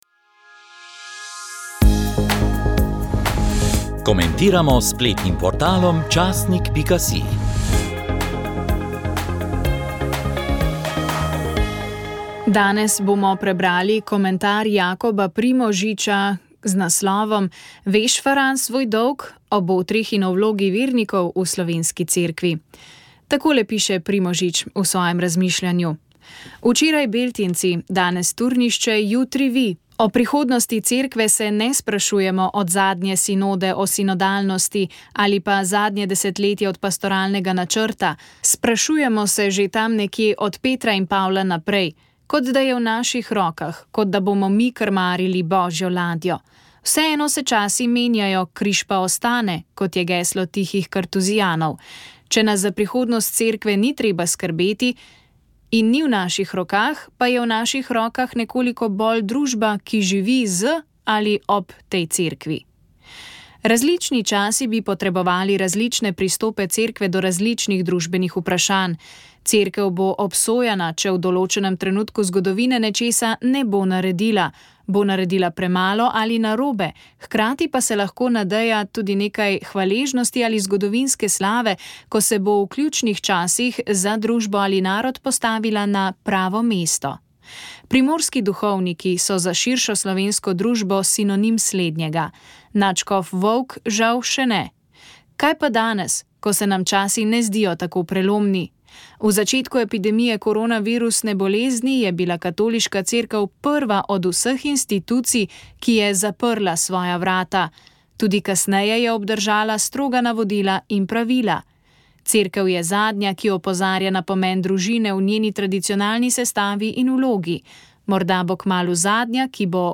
Komentar